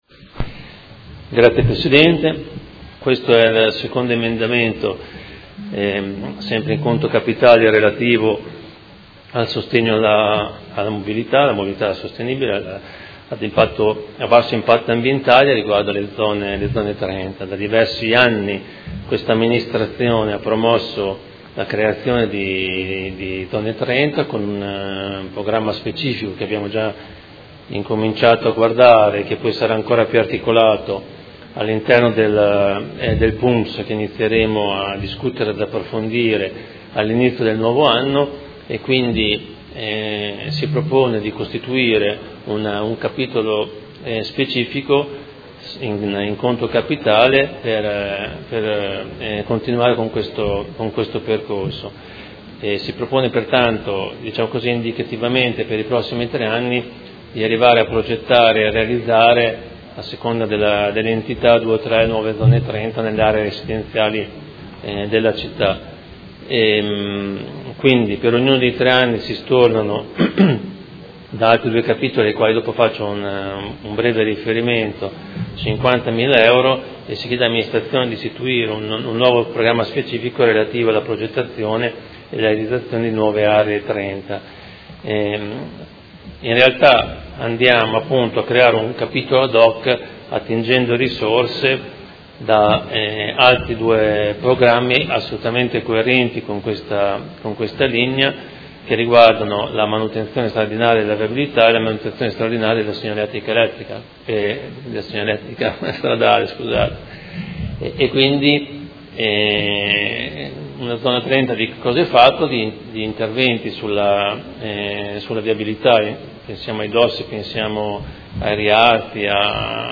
Fabio Poggi — Sito Audio Consiglio Comunale
Seduta del 20/12/2018. Presenta emendamento Prot. Gen. 197165